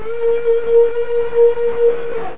Whales